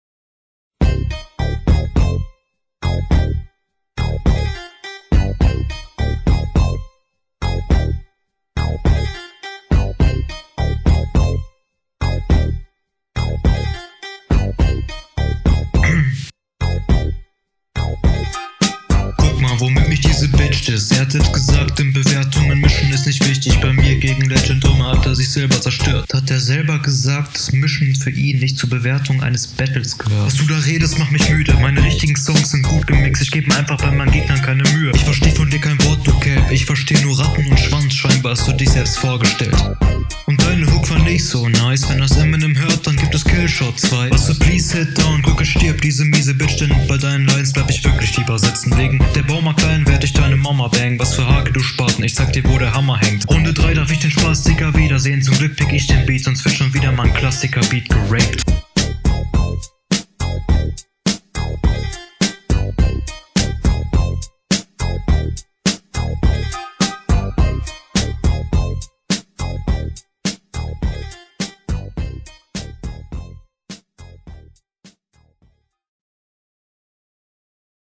Du müsstest dich mal dringend mit einem Equalizer Auseinandersetzen